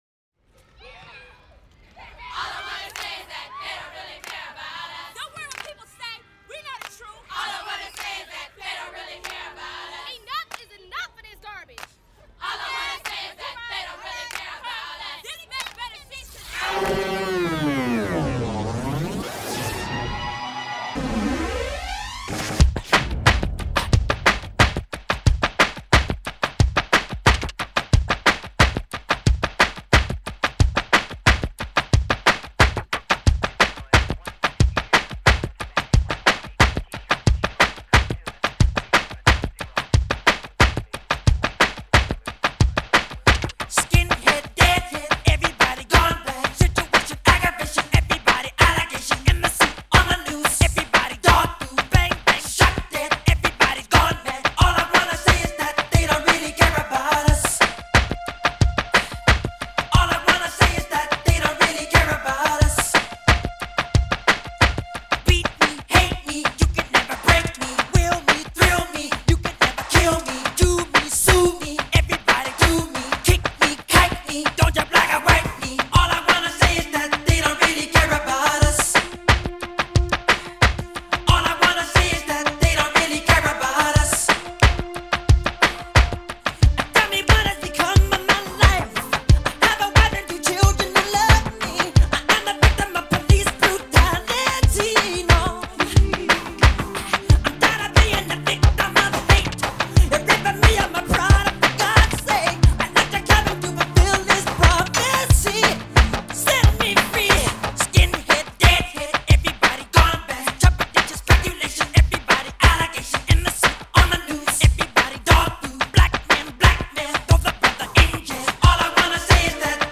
Жанр: Soul